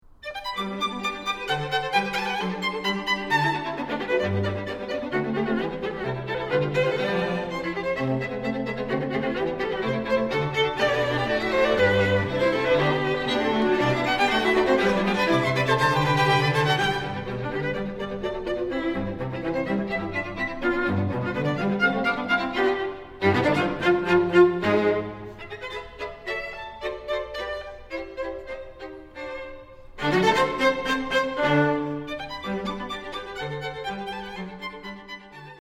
Violin
Viola
Violoncello